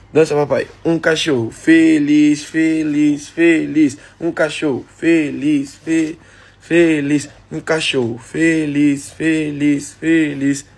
cachorro feliz Meme Sound Effect